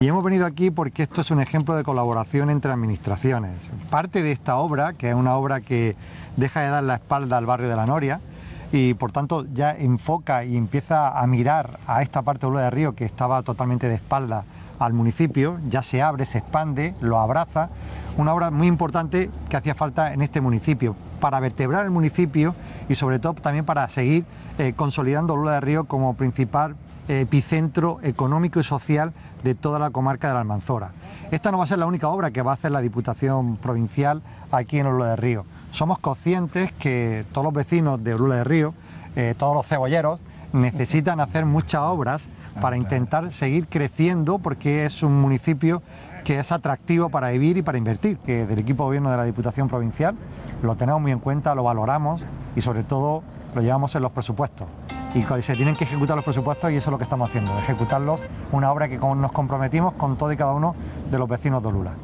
14-01_obra_dipu_olula_presidente_javier_aureliano_garcia.mp3